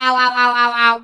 robo_death_01.ogg